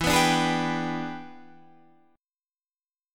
E7#9 chord